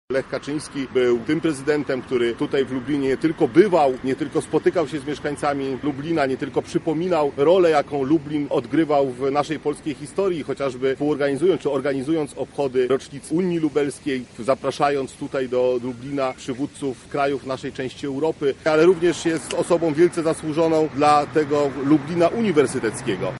To nie jest przypadek, że chcemy, aby pomnik powstał w Lublinie – mówi wicepremier Jacek Sasin: